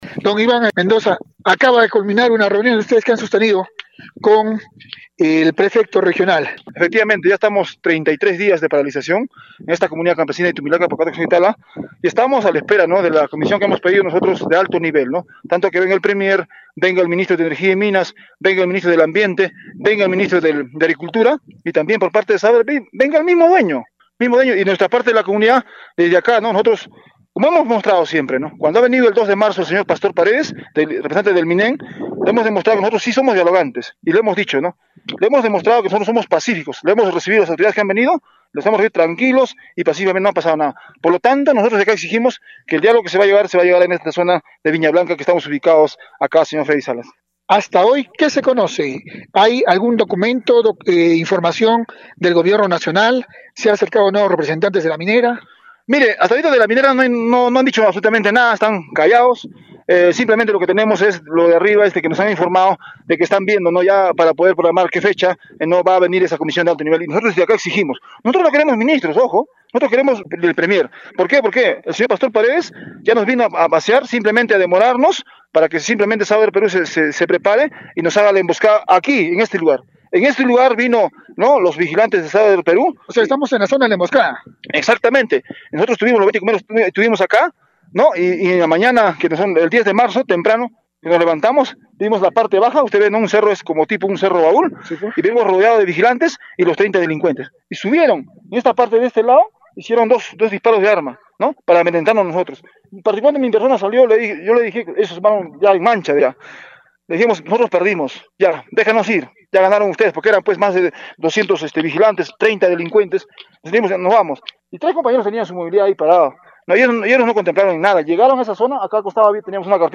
Radio Uno llegó hasta zona de conflicto minero en laguna artificial de Viña Blanca, Moquegua, por encima de los 4 mil metros sobre el nivel mar. En el sector, la comunidad campesina de Tumilaca Pocata Coscore y Tala denuncia que Southern Perú usurpa propiedad para operaciones mineras.